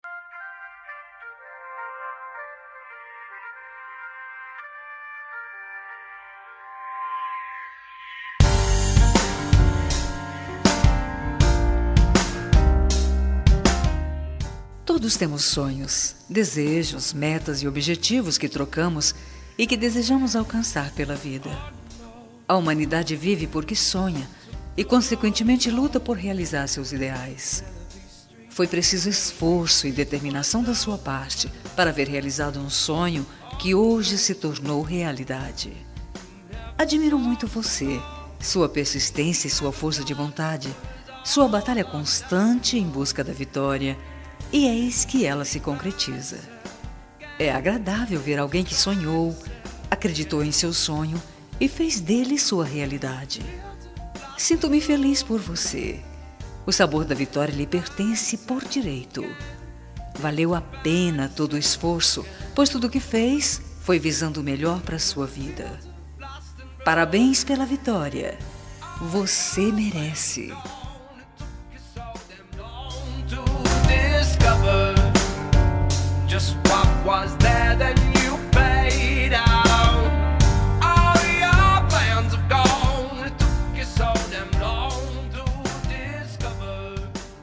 Telemensagem Conquista Vitória – Voz Feminina – Cód: 8152